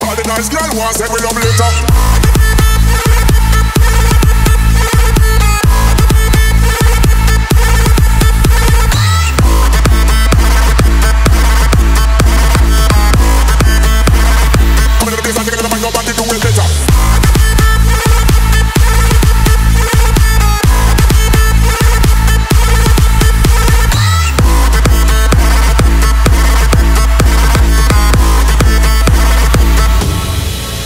• Качество: 320, Stereo
мужской голос
громкие
мощные
восточные мотивы
Electronic
Big Room
быстрые
Стиль: big room